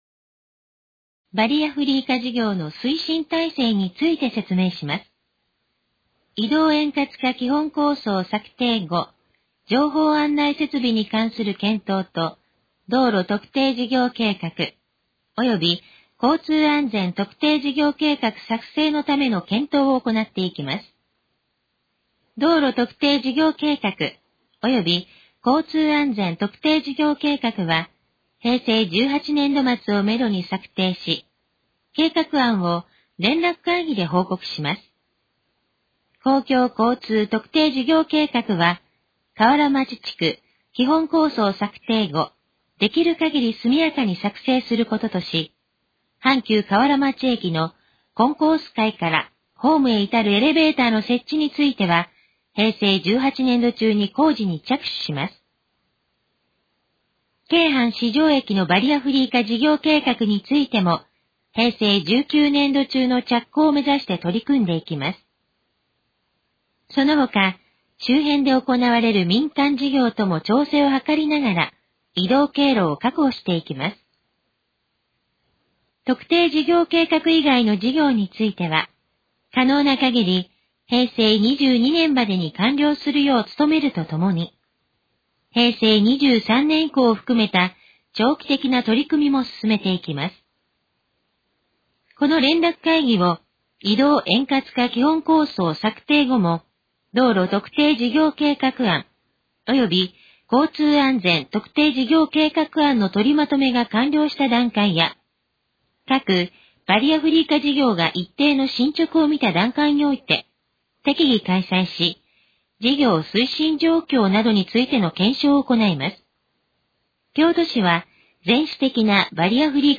このページの要約を音声で読み上げます。
ナレーション再生 約259KB